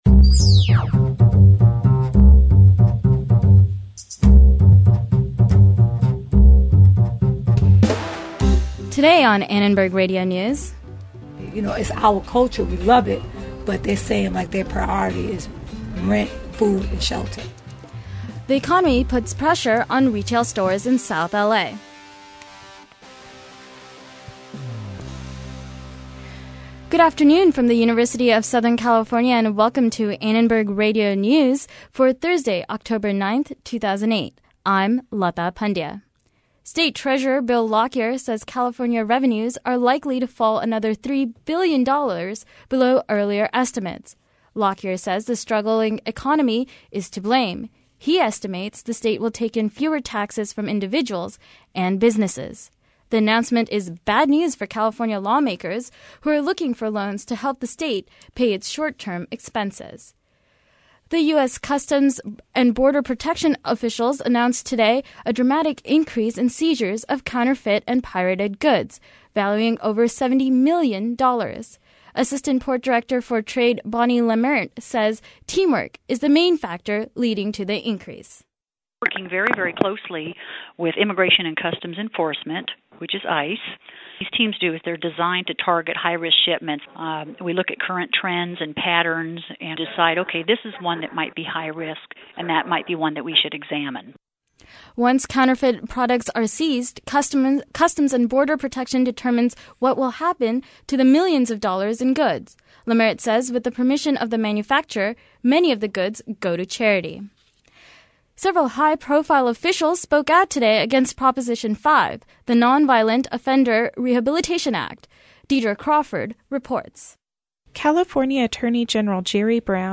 ARN Live Show - October 9, 2008 | USC Annenberg Radio News